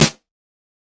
Snare 008.wav